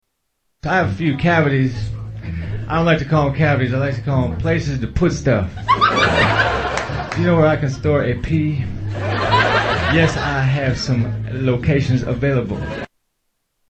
Tags: Comedians Mitch Hedberg Sounds Mitchell Lee Hedberg Mitch Hedberg Clips Stand-up Comedian